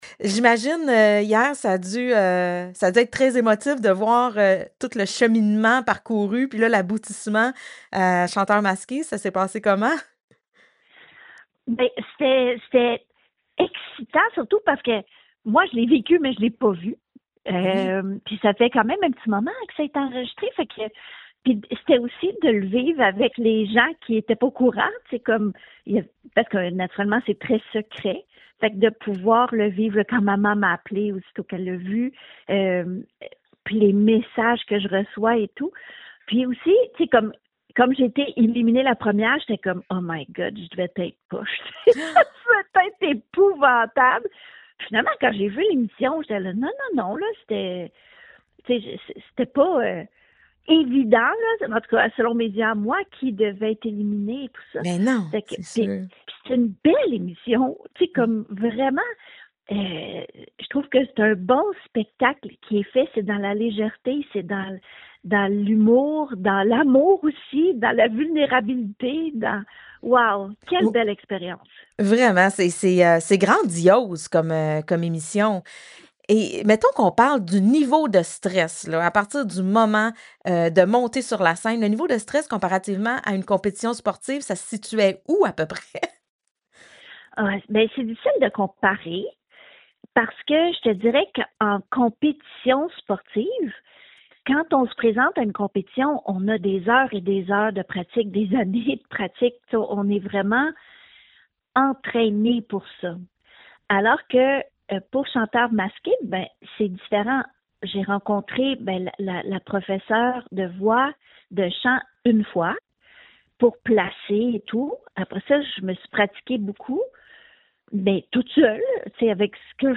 On jase avec la démasquée de Chanteurs Masqués, Sylvie Fréchette.